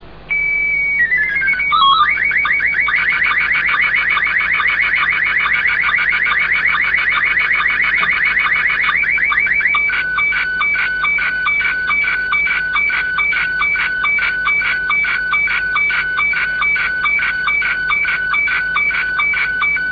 RealAudio FAX/SSTV Sounds
SSTV COLOR SCOTTI 1 110 sec. 256/256